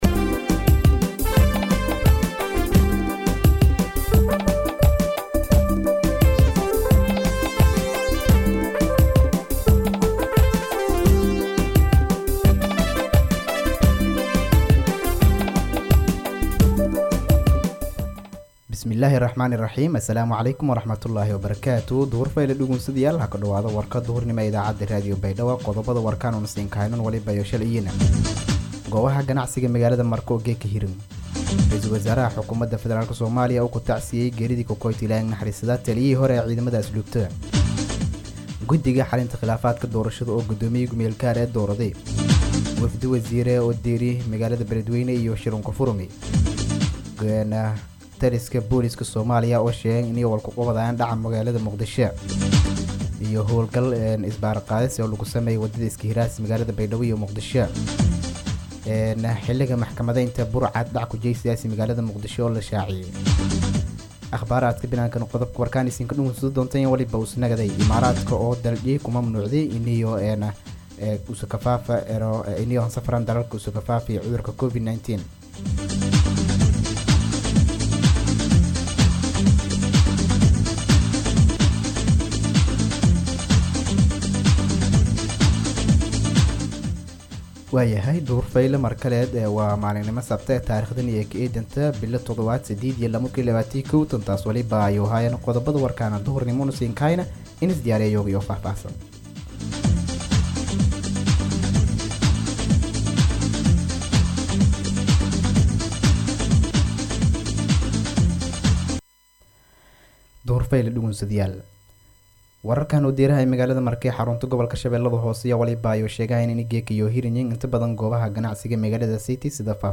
DHAGEYSO:- Warka Duhurnimo Radio Baidoa 4-7-2021